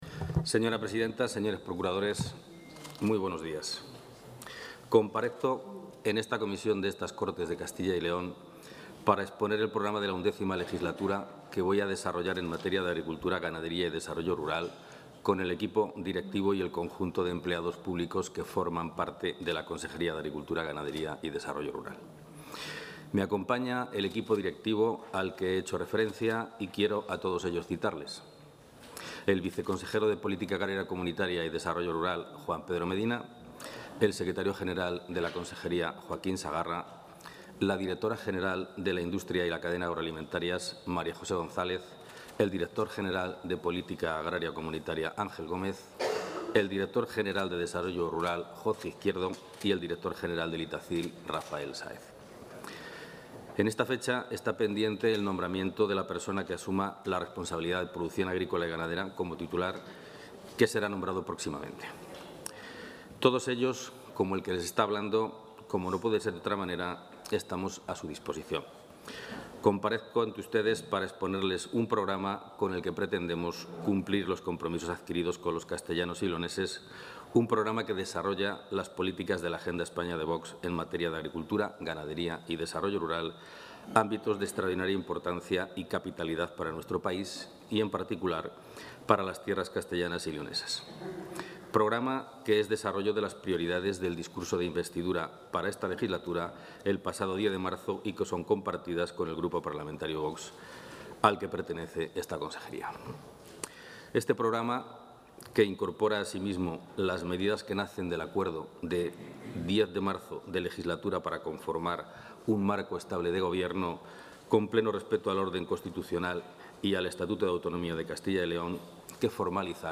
Gerardo Dueñas ha comparecido este miércoles en la sede de las Cortes de Castilla y León para exponer el programa que...
Comparecencia consejero Agricultura, Ganadería y Desarrollo Rural.